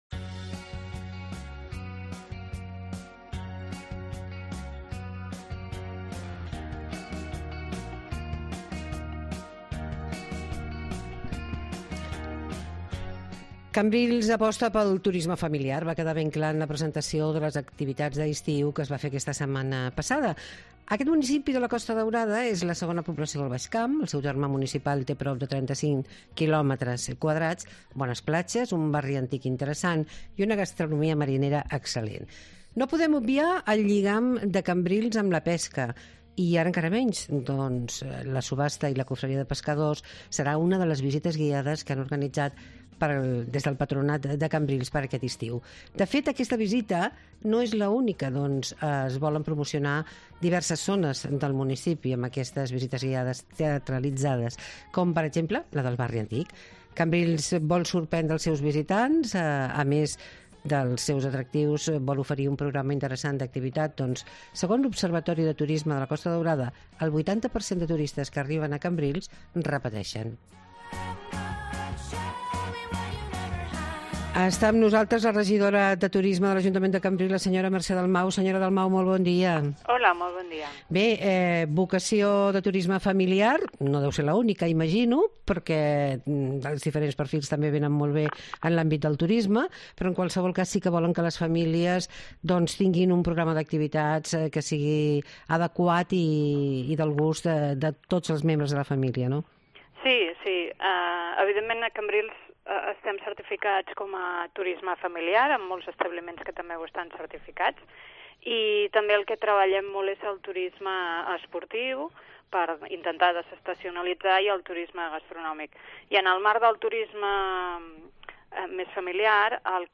A Cambrils, han preparat un programa d'activitats per rebre als visitants, els detalls ens els donava la regidora de Turisme, Mercè Dalmau